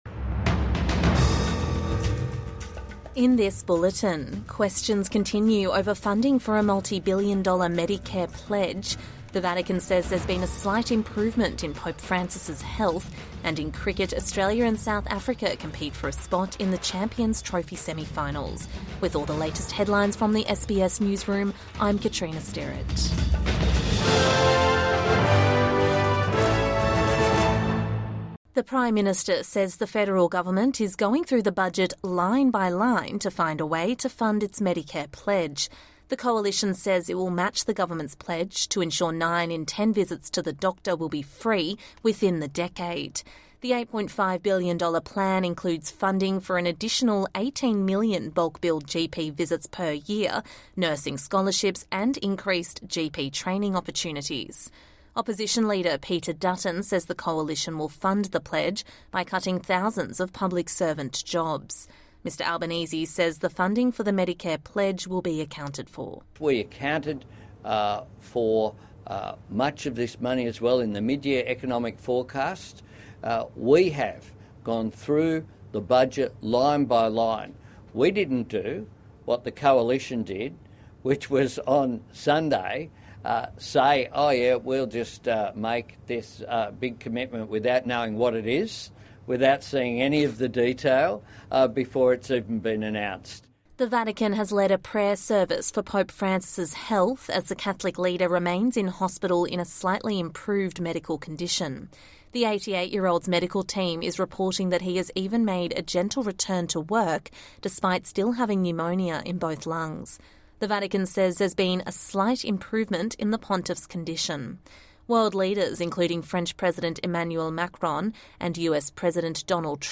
Midday News Bulletin 25 February 2025